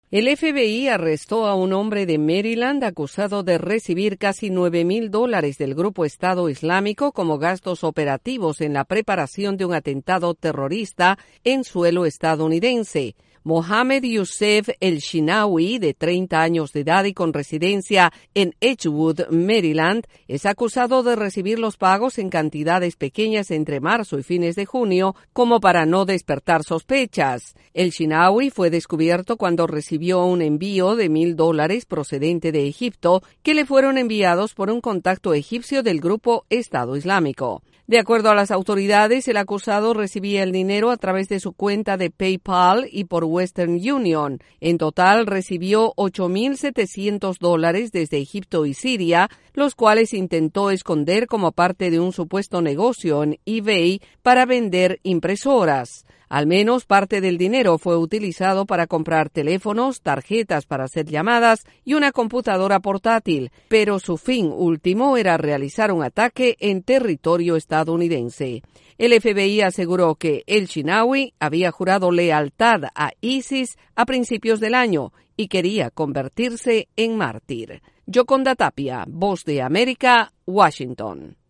Autoridades estadounidenses arrestan a un individuo acusado de recibir dinero del autodenominado grupo Estado islámico. Desde la Voz de América en Washington informa